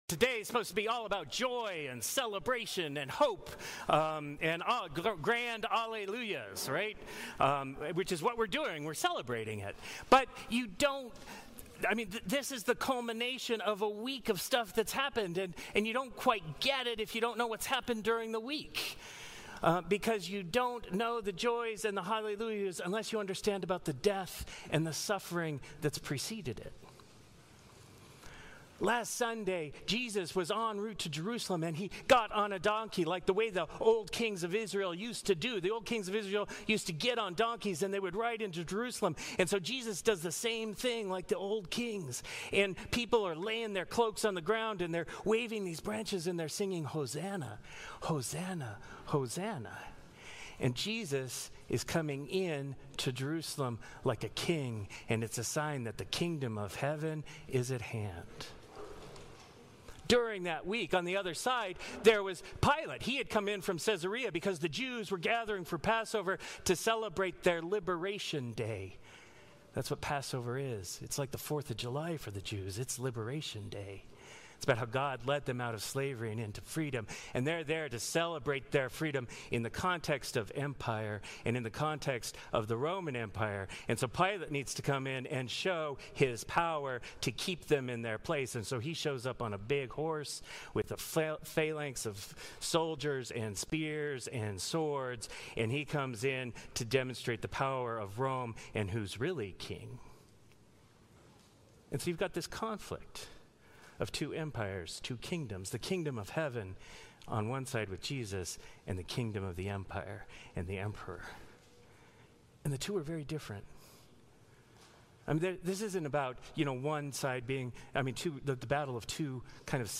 Sermons | Grace Episcopal Church
Easter Day